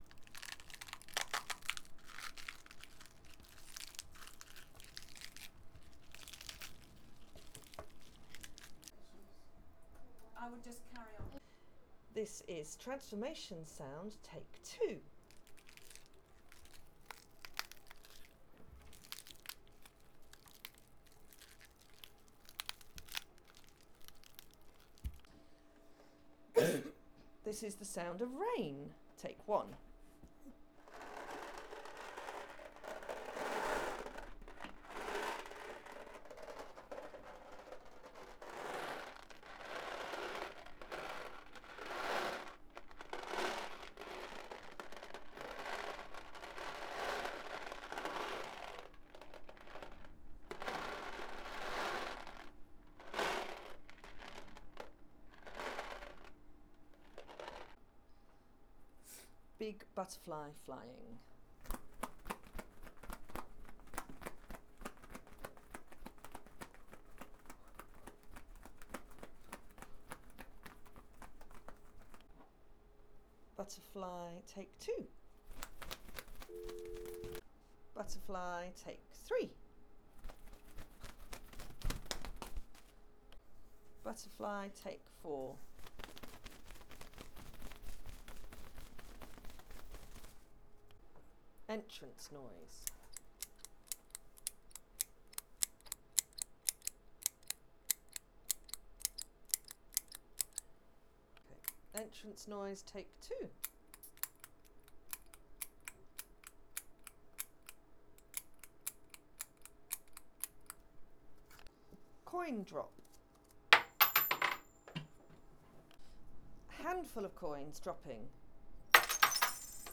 Session 5: Foley artistry
Here’s a glimpse of what we managed to record which we hope to use in our film.
foley-sounds.wav